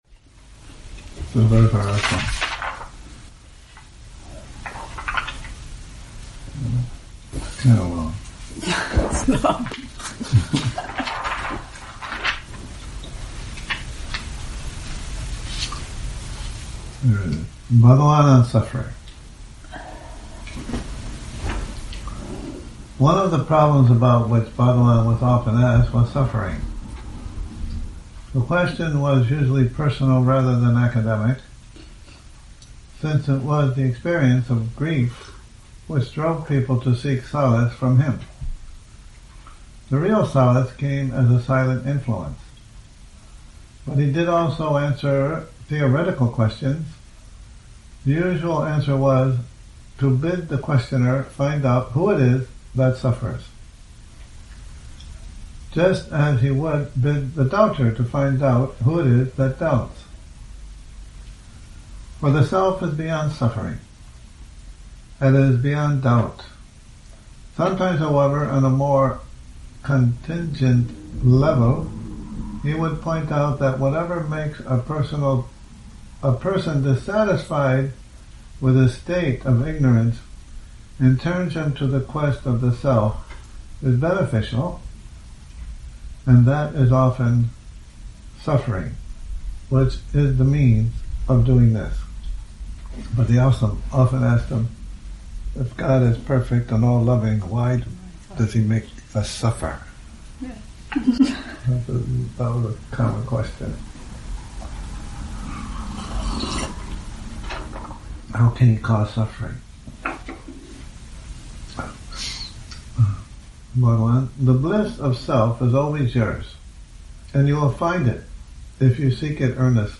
2019-12-01 reading, Bhagavan on Suffering